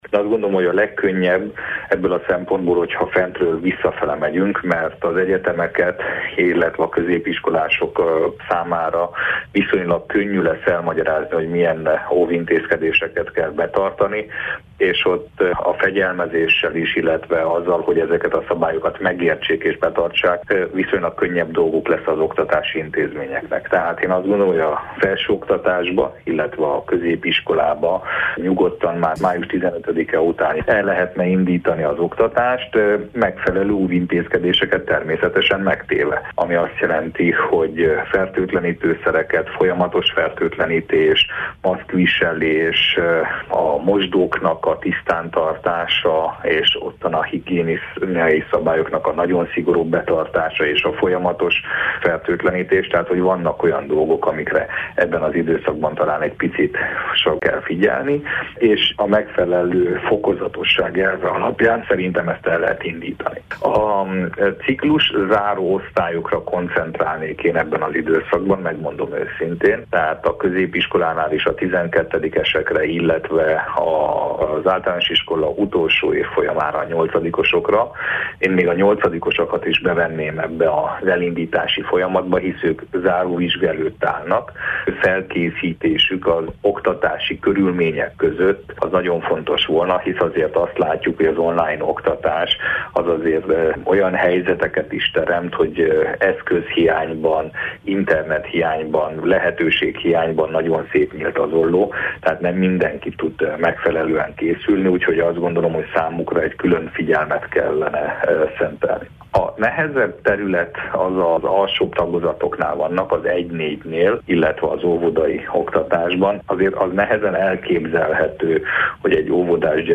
Szabó Ödön képviselőt kérdezte